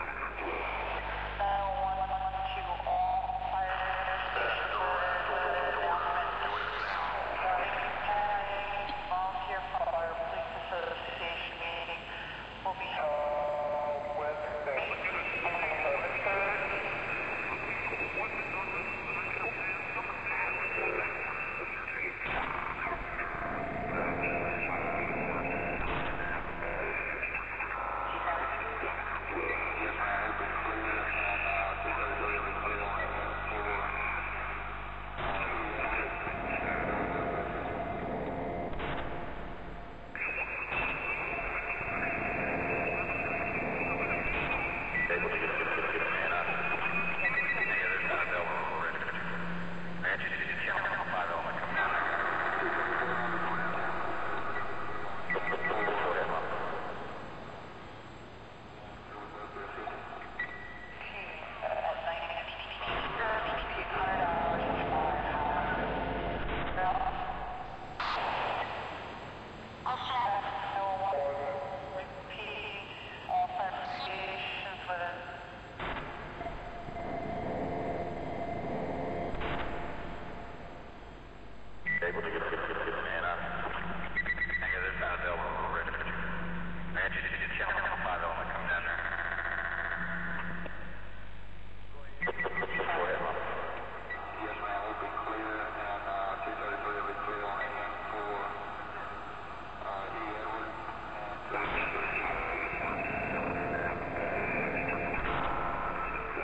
radioChatterLoop.ogg